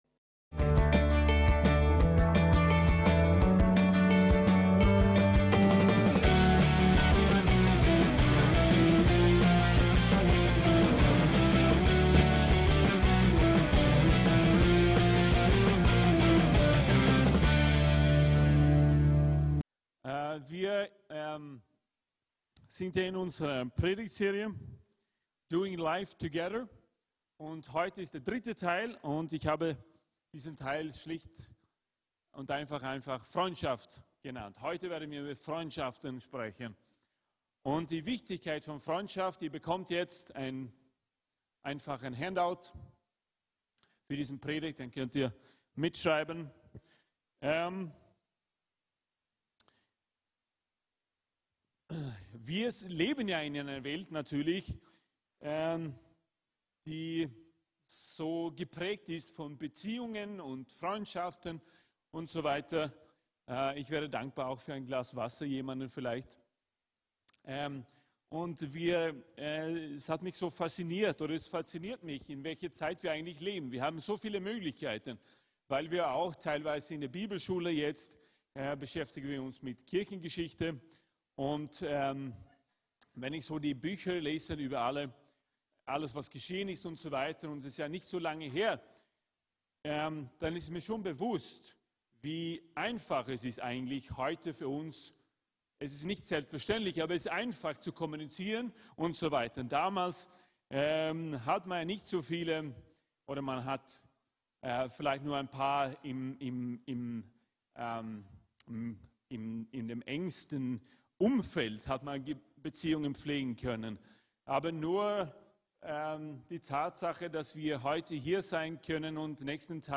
FREUNDSCHAFT - TEIL 3 - DOING LIFE TOGETHER ~ VCC JesusZentrum Gottesdienste (audio) Podcast